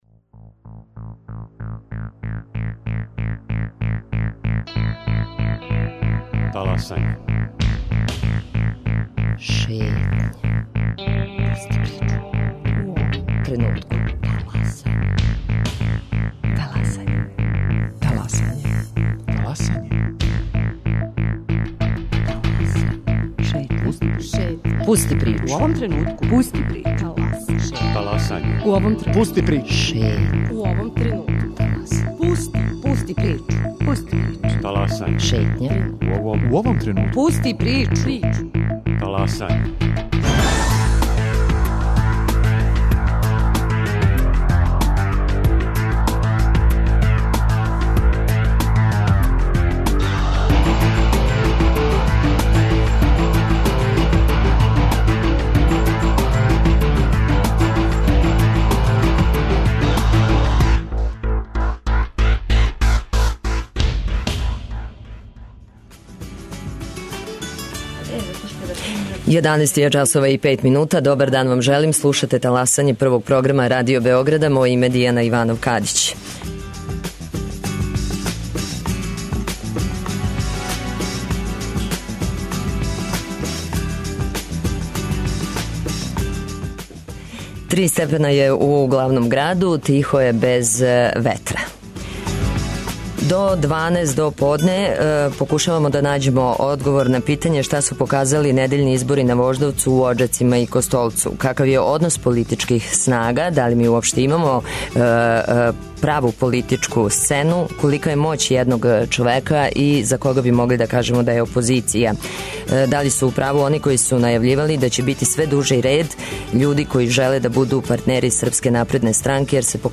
Гости: Весна Пешић, социолог и Дејан Вук Станковић, политички аналитичар